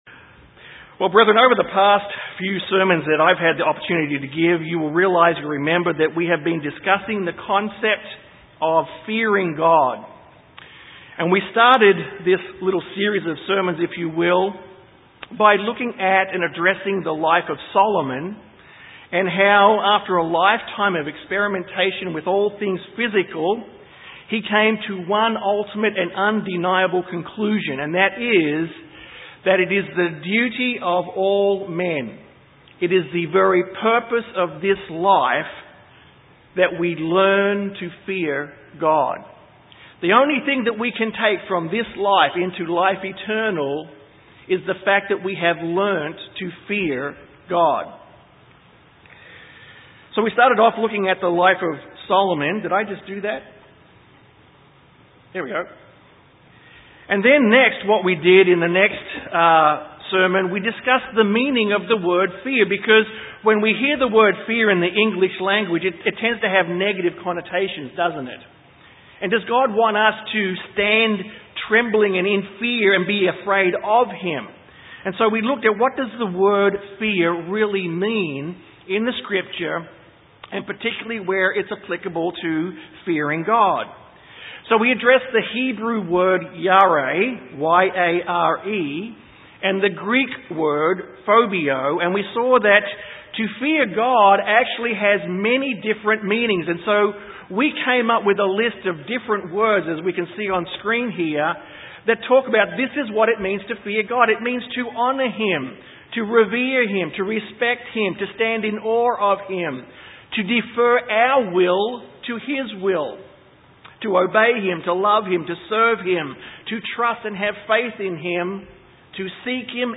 God does not want us to "live in fear of Him" but wants us to have a healthy respect and reverence for Him. This sermon discusses how to show the proper "Fear" towards God.